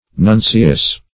Nuncius \Nun"ci*us\, n.; pl. Nuncii. [L.] (Roman & Old Eng.